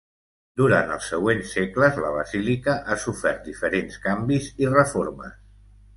Pronounced as (IPA) [ˈseɡ.ɡləs]